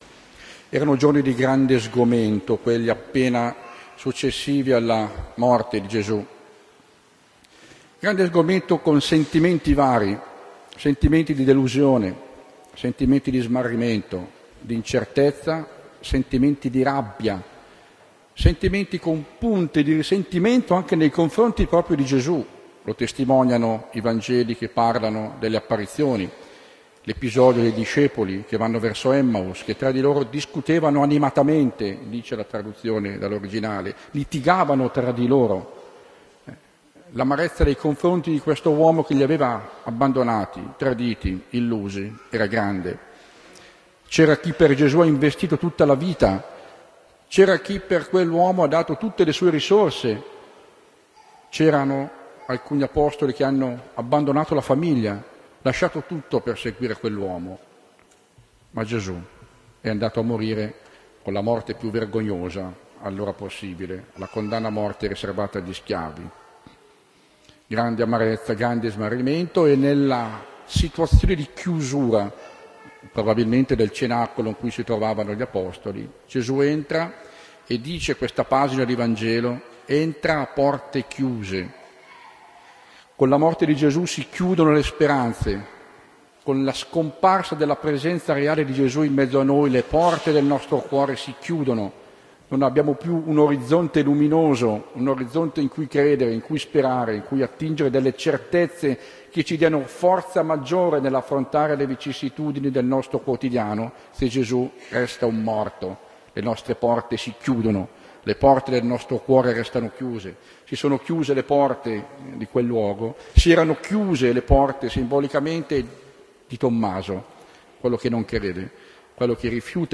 S.MESSA RADUNO GIOVANI 1 maggio 2011 – AUDIO
11-Omelia.ogg